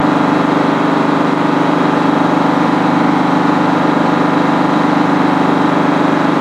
Highwayman Truck Steady